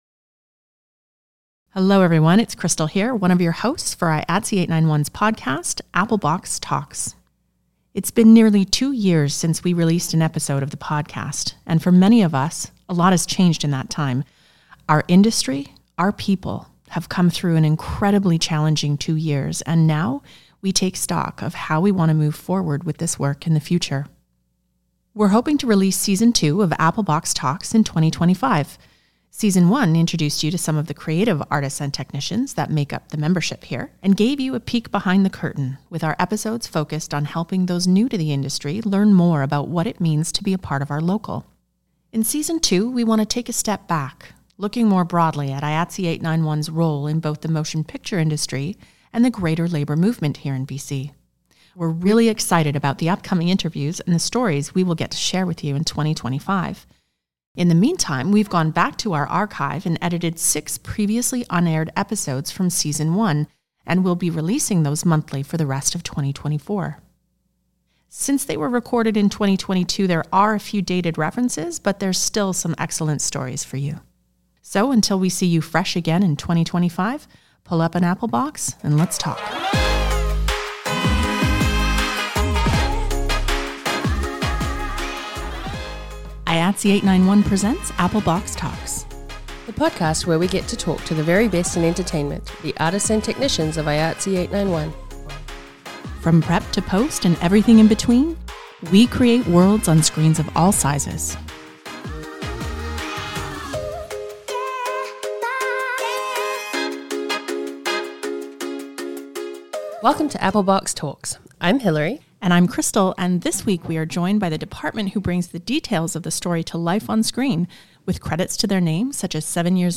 Kicking us off is an amazing interview with local legend and 891 Props Department member